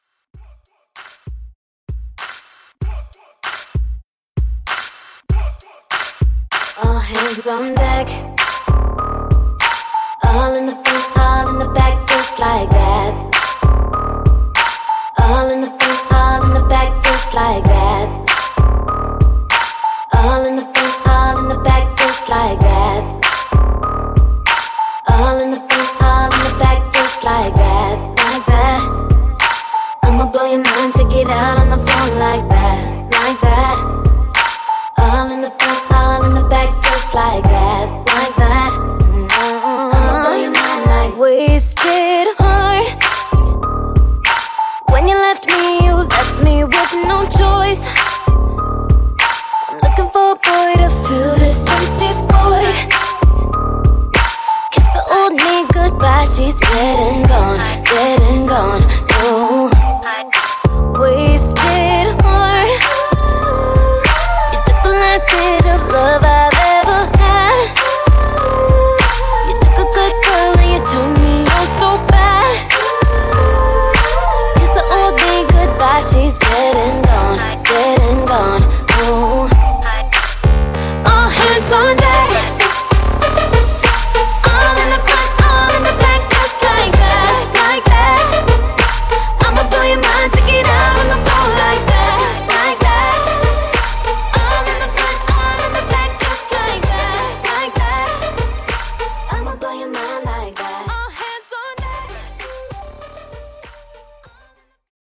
R&B Redrum)Date Added